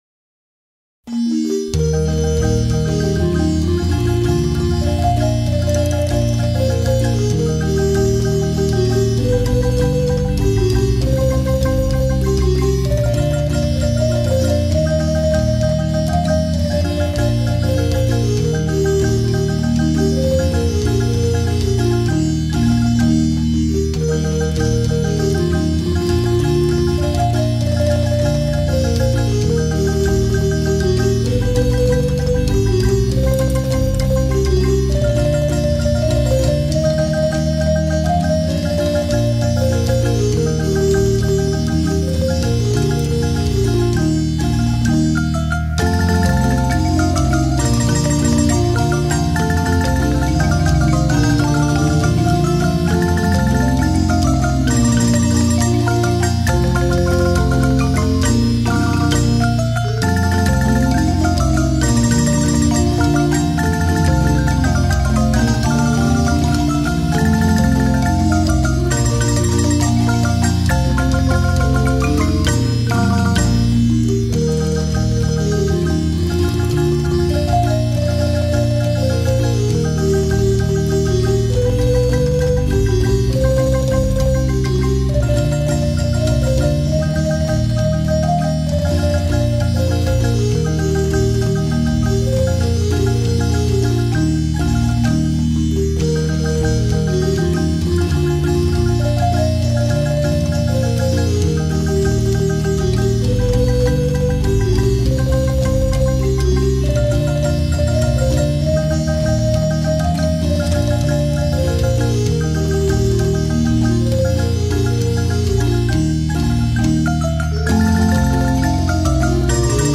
Las obras, ordenadas de 1 a 8, fueron grabadas en estudio durante el año 2006 con la marimba escuadra (marimba grande y un tenor) de la Casa de la Cultura de Santa Cruz, Guanacaste.
MUSICA, GUANACASTE, MARIMBA